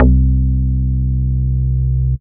15Bass15.WAV